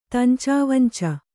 ♪ tancā vanca